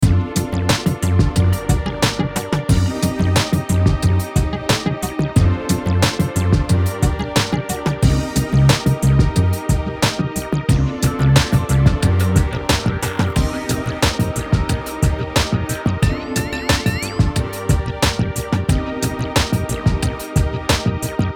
テンポ90のゆったりとしたダンス着信音。
月明かりの下で、ロマンチックにワルツを踊るような、幻想的なサウンドです。